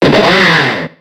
Cri d'Escroco dans Pokémon X et Y.